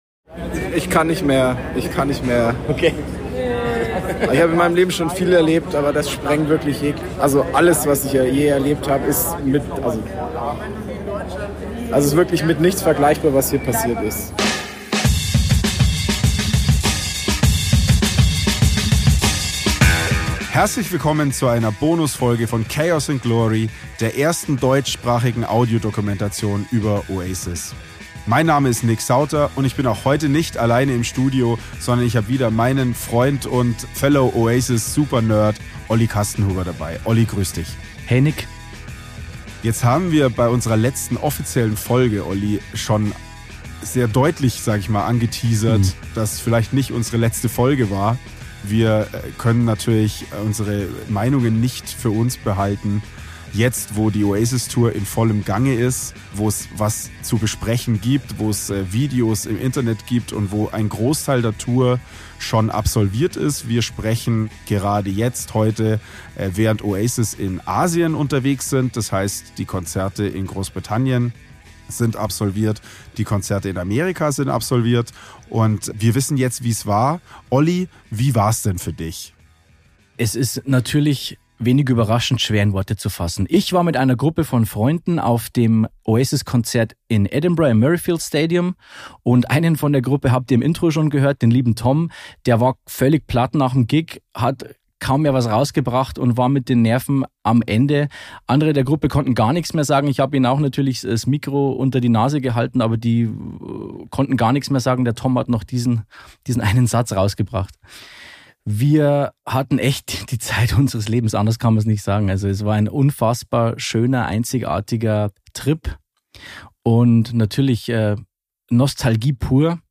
Wir hören Stimmen von Fans, die das Comeback nicht nur gesehen, sondern gespürt haben – vom ersten Akkord bis zum letzten hektisch aufgenommenen Handyvideo. Dazu erzählen wir von unseren eigenen Erlebnissen: Wie es war, Teil dieser Masse aus Erwartung, Nostalgie und purer Euphorie zu sein. Der Bonustrack ist ein akustischer Blick hinter die Kulissen eines Konzerts, das mehr war als nur Musik.